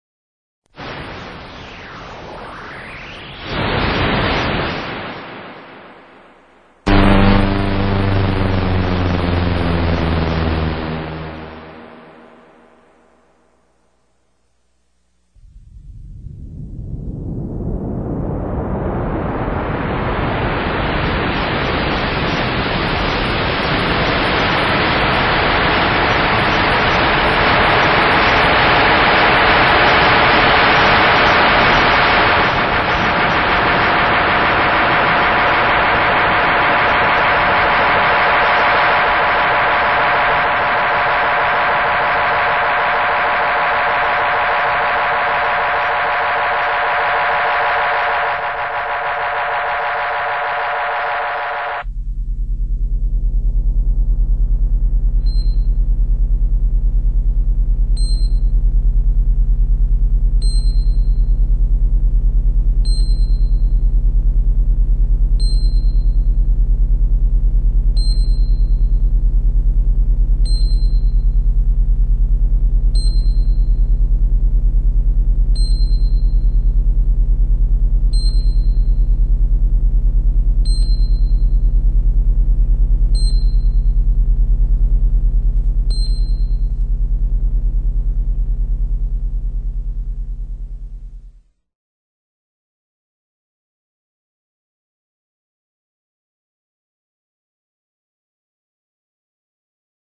Театральные шумы звуки скачать, слушать онлайн ✔в хорошем качестве